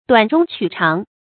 發音讀音
成語拼音 duǎn zhōng qǔ cháng